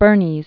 (bûrnēz, -nēs, bûr-nēz, -nēs)